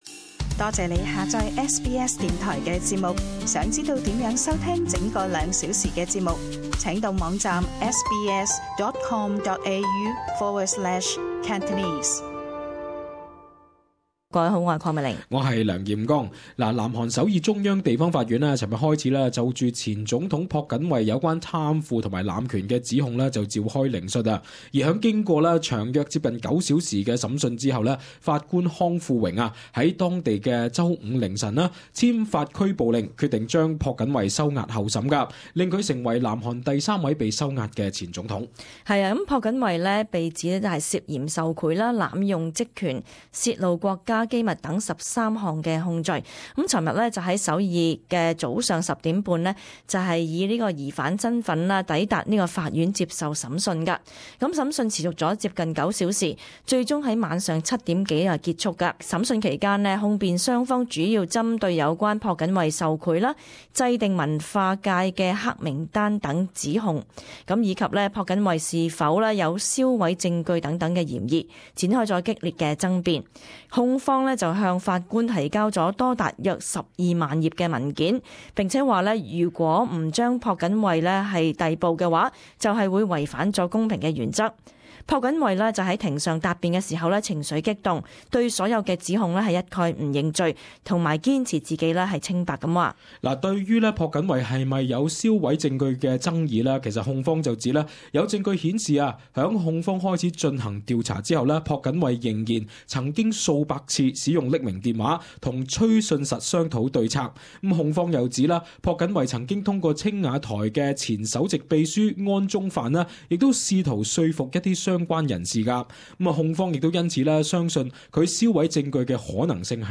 【時事報導】南韓前總統朴槿惠被判收押候審